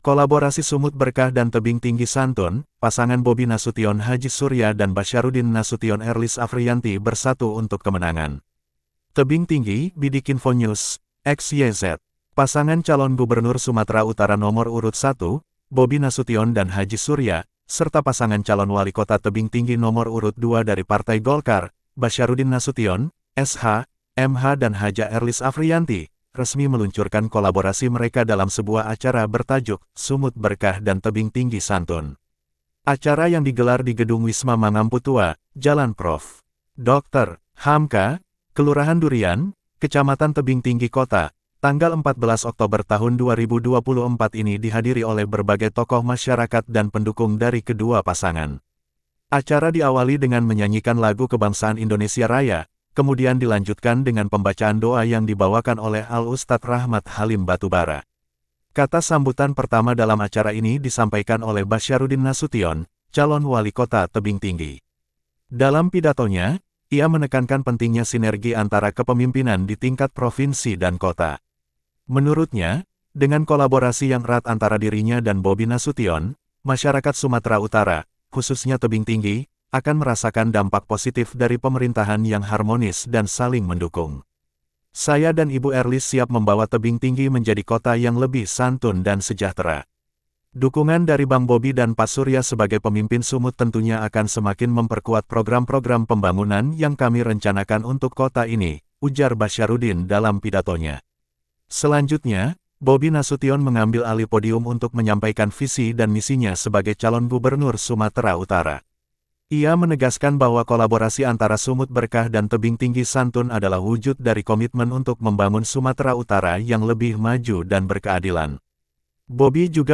Acara yang digelar di Gedung Wisma Mangampu Tua, Jalan Prof. Dr. Hamka, Kelurahan Durian, Kecamatan Tebing Tinggi Kota,( 14/10 ) ini dihadiri oleh berbagai tokoh masyarakat dan pendukung dari kedua pasangan.